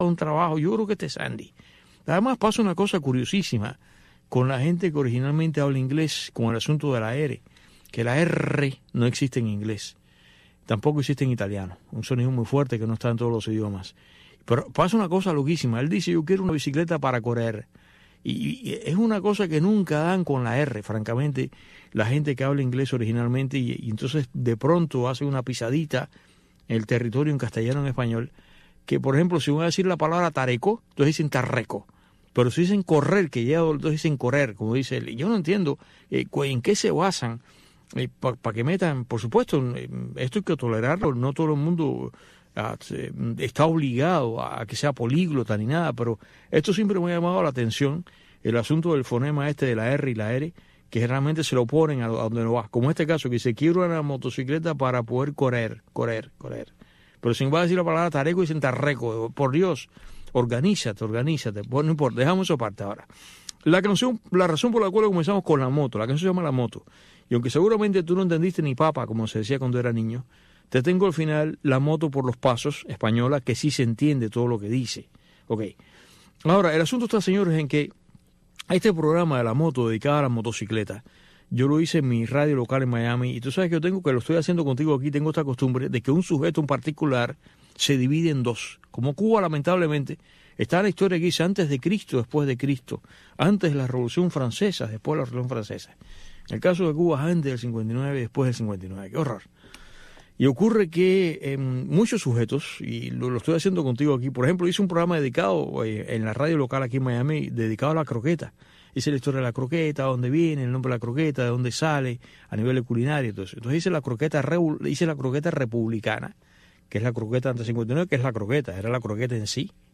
en este espacio informativo en vivo